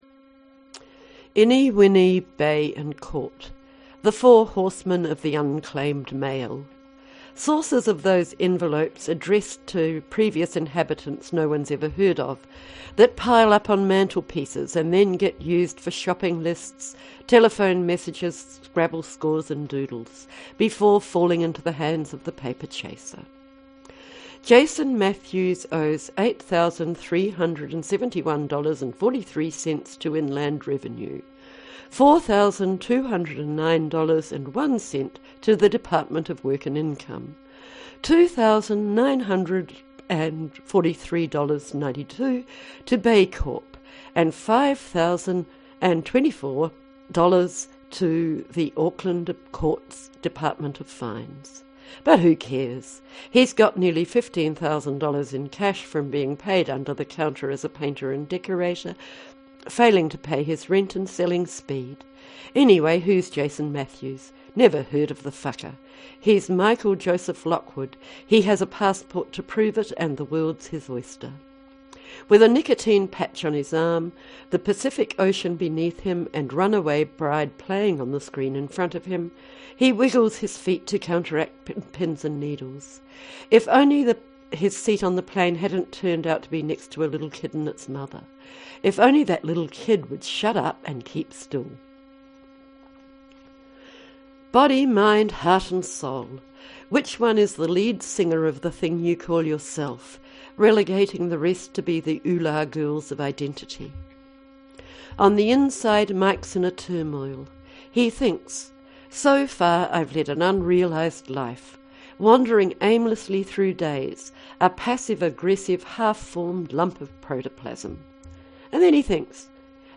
reading from Curriculum Vitae with backing music